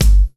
Kick (Hey!).wav